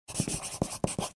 Звуки рисования
Подпись автограф карандашом